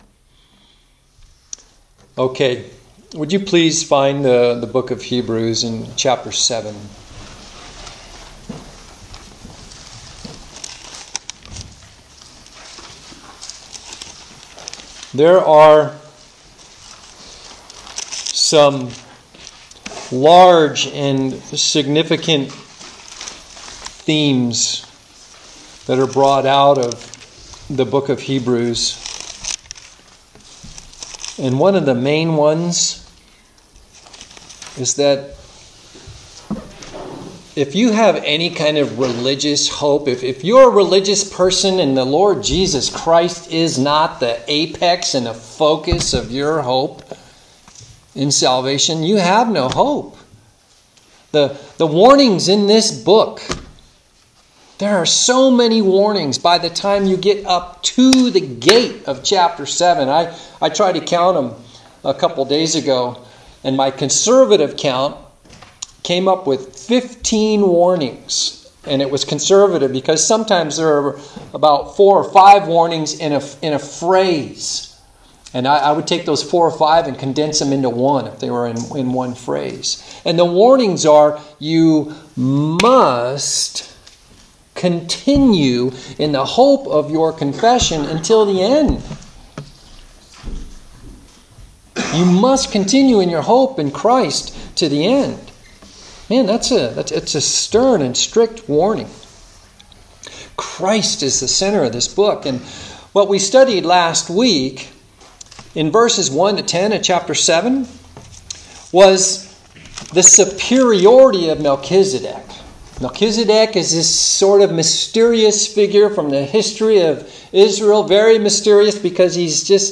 Heb 7:1-10 Next Sermon Heb 7:20-28 Christ, the Interceding Priest. missing the first few minutes